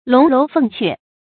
龍樓鳳闕 注音： ㄌㄨㄙˊ ㄌㄡˊ ㄈㄥˋ ㄑㄩㄝ 讀音讀法： 意思解釋： 帝王宮闕。